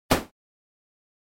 SE（パン）
パン。たたく音。銃声。